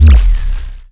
sci-fi_electric_pulse_hum_02.wav